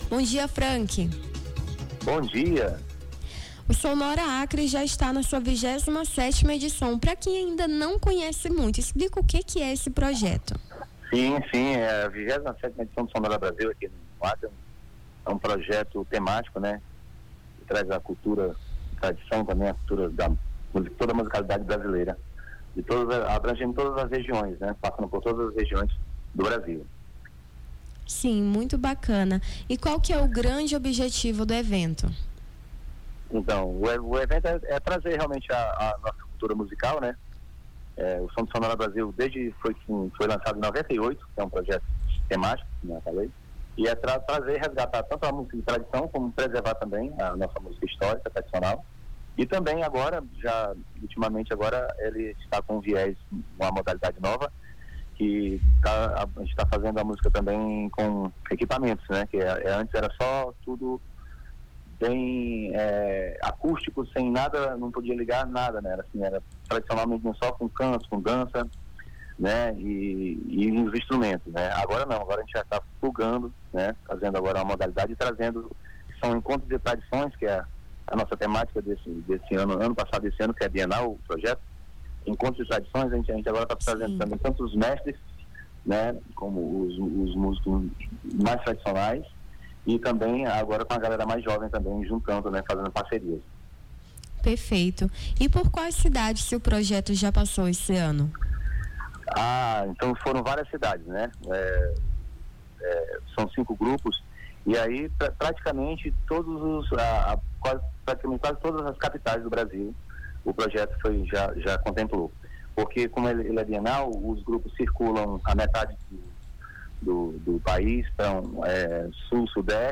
Nome do Artista - CENSURA - ENTREVISTA (SONORA BRASIL) 17-07-25.mp3